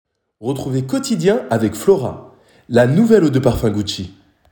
Version 1 dynamique